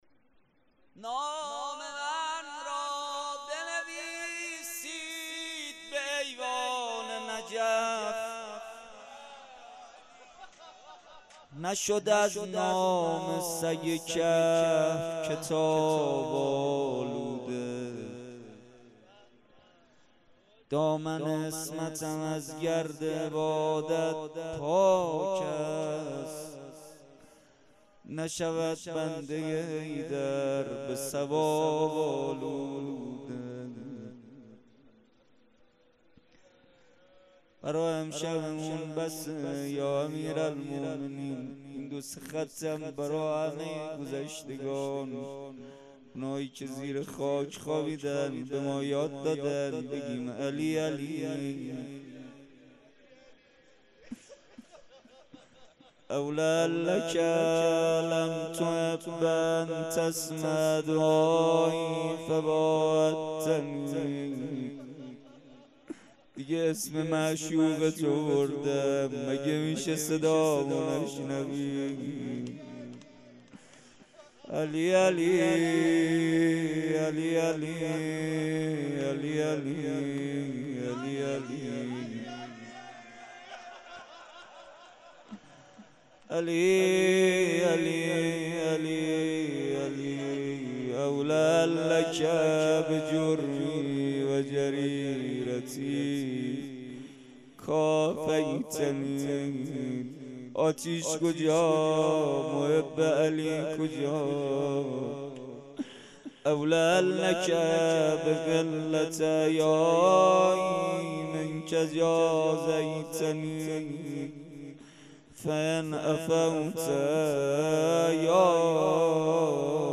شب هفتم ماه رمضان با مداحی کربلایی محمدحسین پویانفر در ولنجک – بلوار دانشجو – کهف الشهداء برگزار گردید.
دعا ومناجات روضه لینک کپی شد گزارش خطا پسندها 0 اشتراک گذاری فیسبوک سروش واتس‌اپ لینکدین توییتر تلگرام اشتراک گذاری فیسبوک سروش واتس‌اپ لینکدین توییتر تلگرام